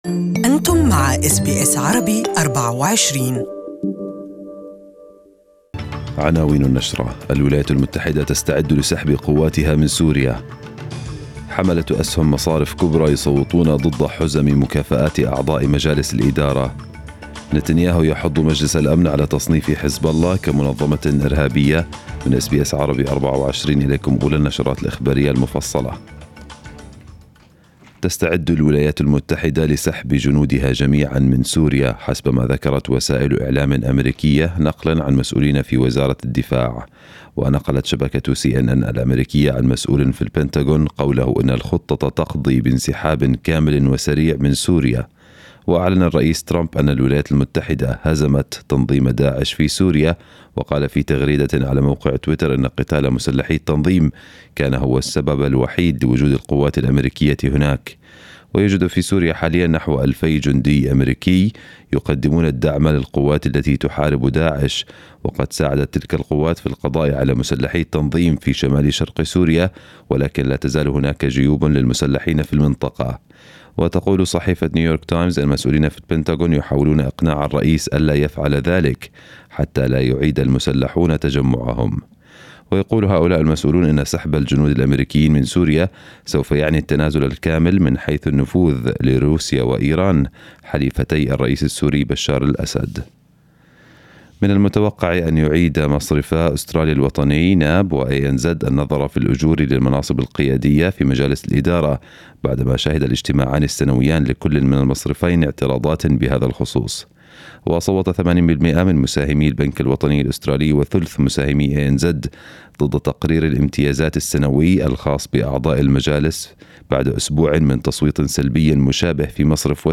نشرة الأخبار المفصلة لهذا الصباح